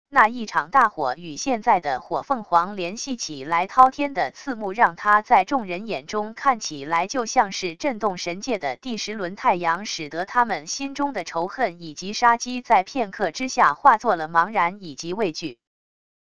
生成语音 下载WAV